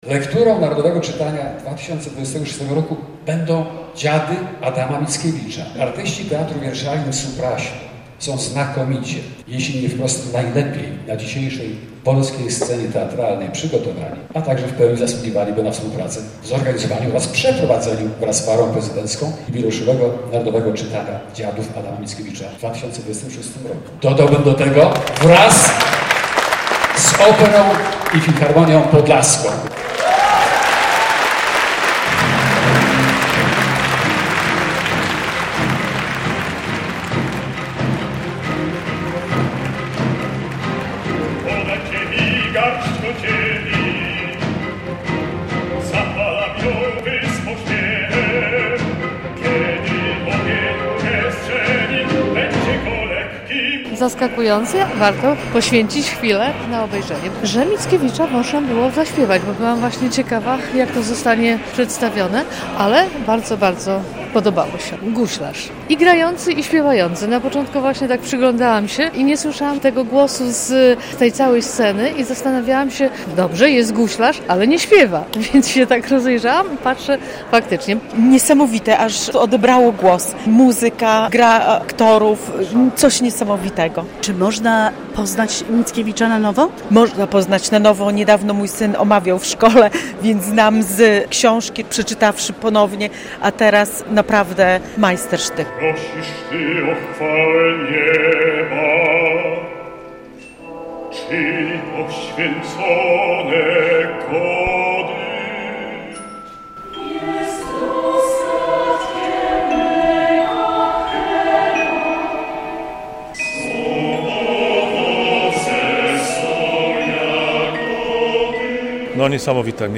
recenzuje